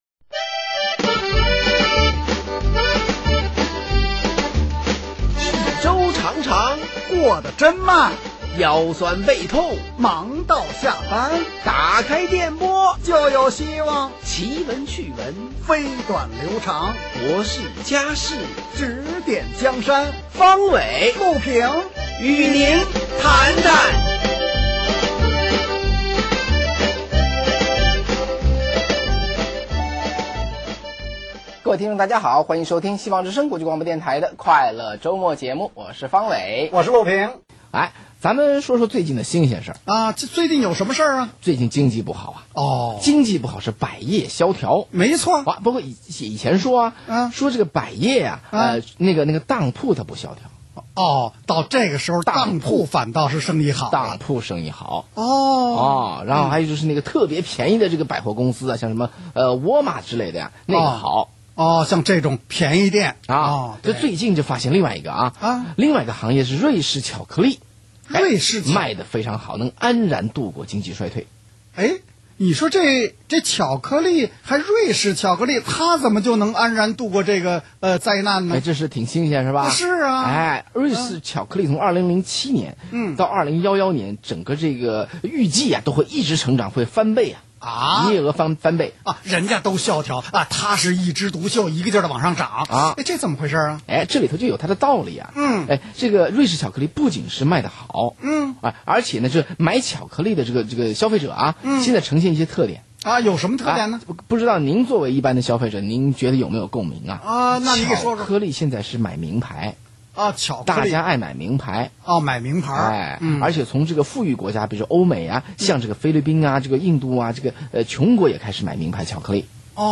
请听希望之声国际广播电台周末脱口秀
本节目诙谐轻松，畅谈时事，在全球许多落地分台拥有大量忠实听众，您不可错过!